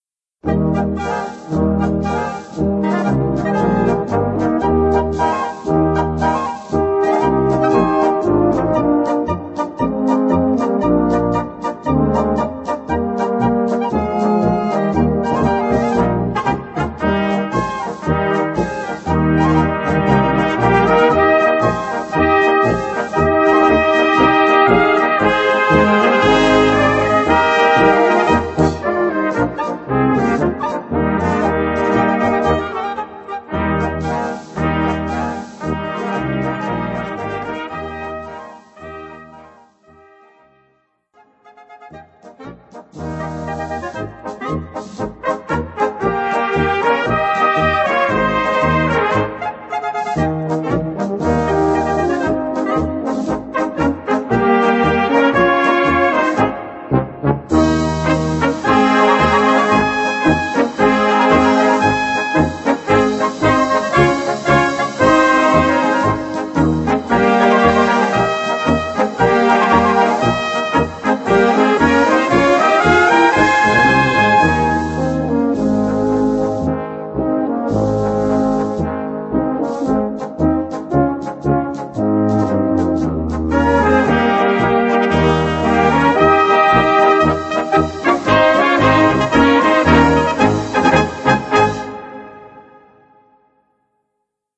Gattung: Polka
A4 Besetzung: Blasorchester Zu hören auf